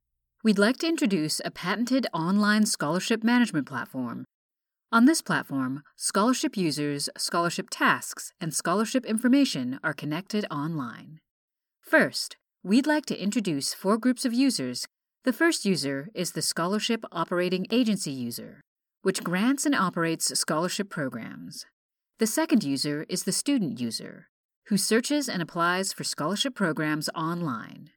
ELearning Demo
Middle Aged
My warm and confident delivery ensures your message resonates with your audience.